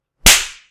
뺨 때리는 소리
bbyam-ddaerineun-sori.mp3